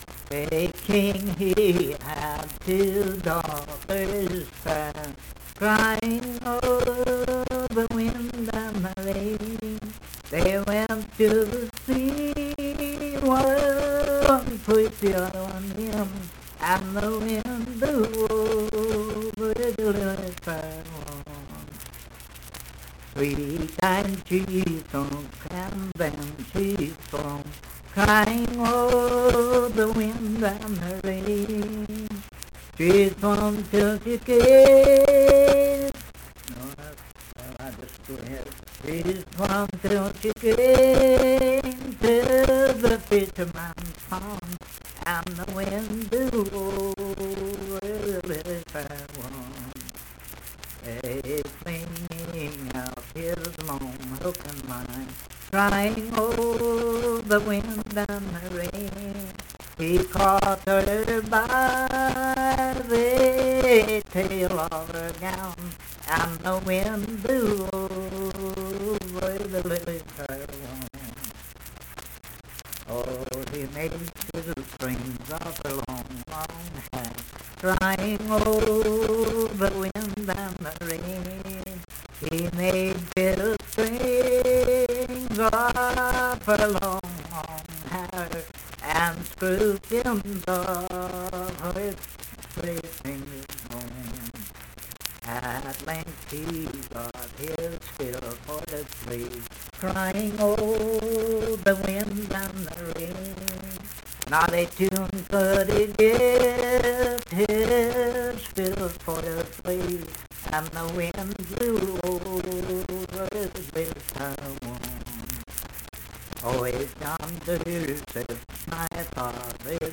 Unaccompanied vocal music
Voice (sung)
Huntington (W. Va.), Cabell County (W. Va.)